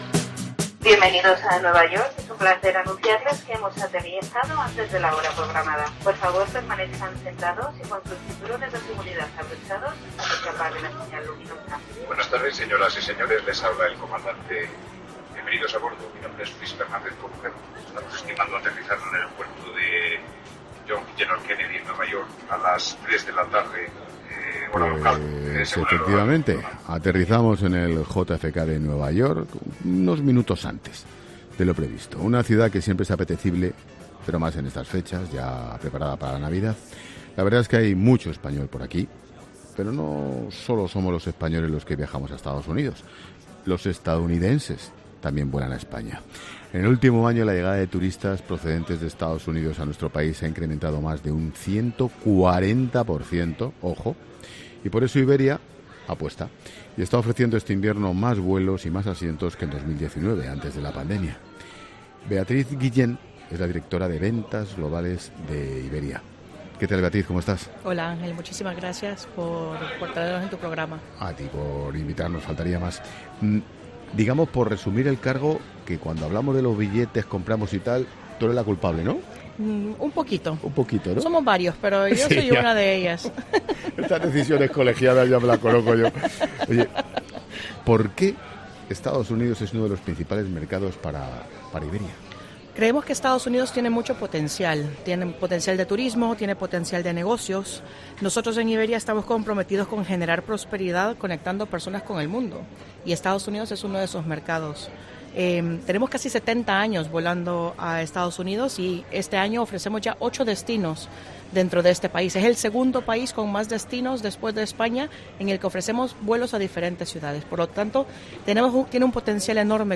Hoy se ha encendiendo 'La Linterna' desde Nueva York, una ciudad que siempre es apetecible para volar, pero más en estas fechas.